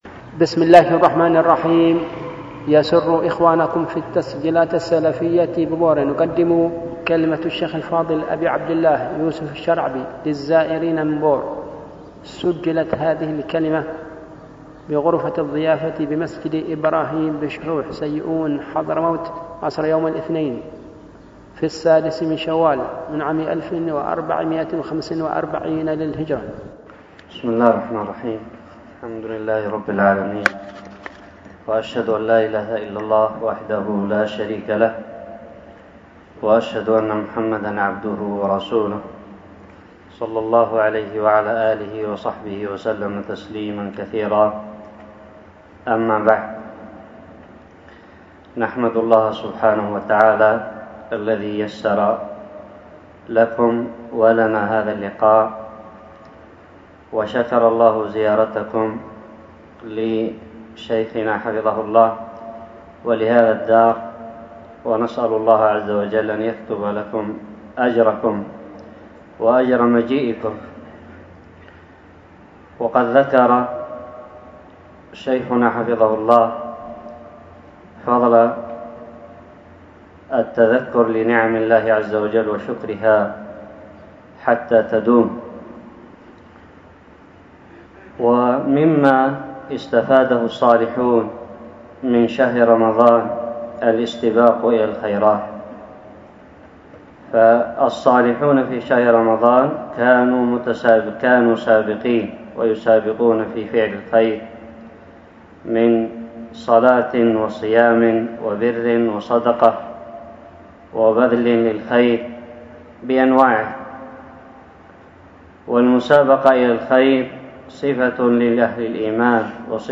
كلمة
بمناسبة زيارة أهل بور
ألقيت بغرفة الضيافة بمسجد إبراهيم بشحوح/ سيئون حضرموت اليمن